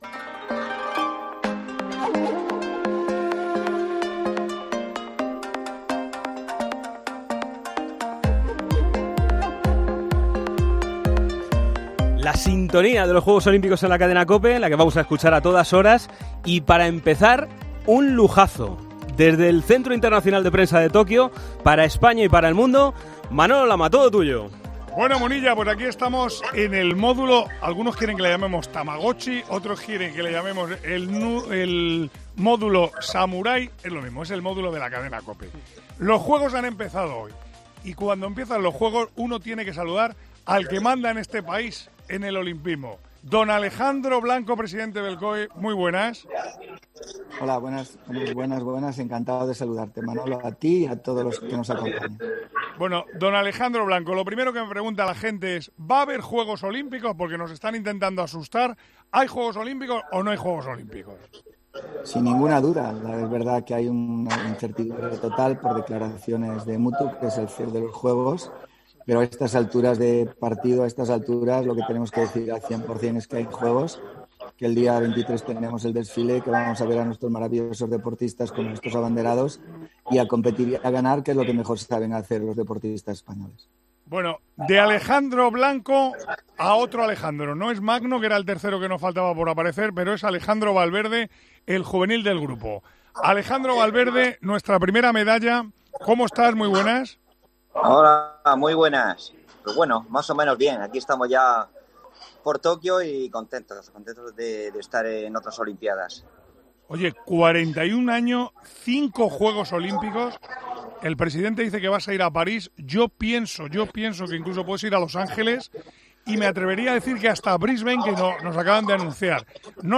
Manolo Lama habla desde Tokio con Alejandro Blanco, presidente del COE, y los deportistas Alejandro Valverde, Carla Suárez y Orlando Ortega de sus sensaciones de cara al evento.